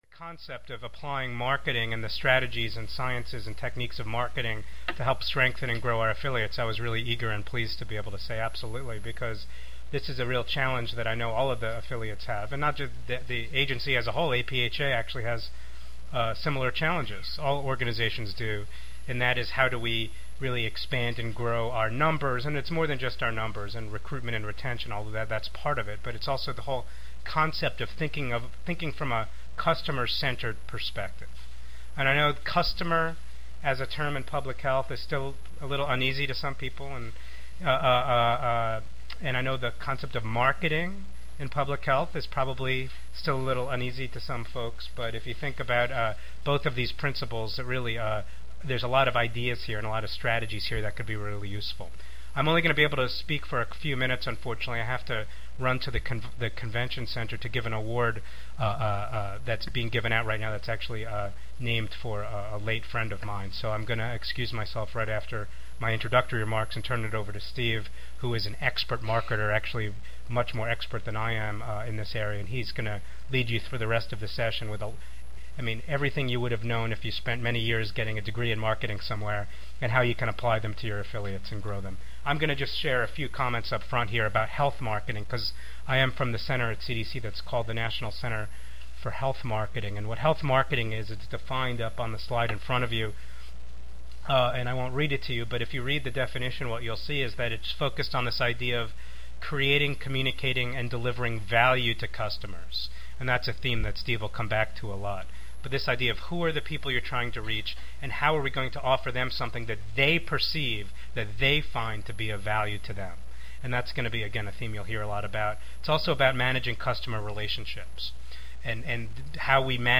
This presentation will recommend the implementation of a customer-centered marketing approach that will grow affiliate membership communities.